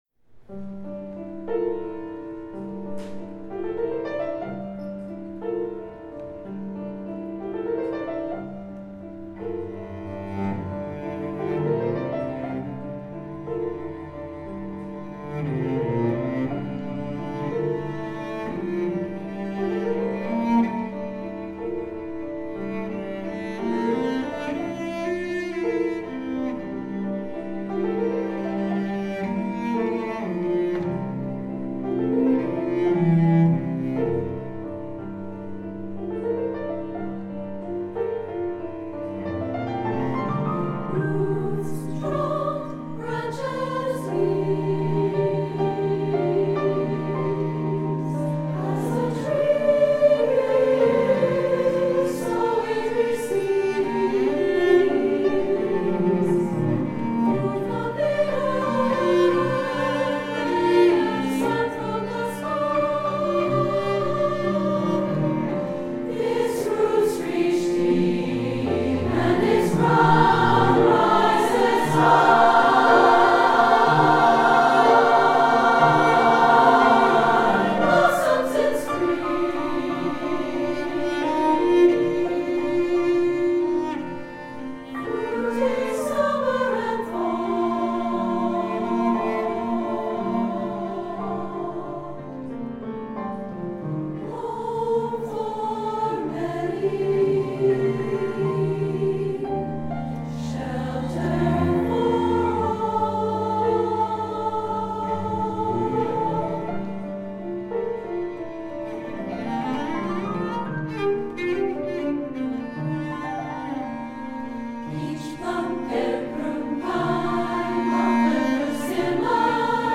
SSA, cello, piano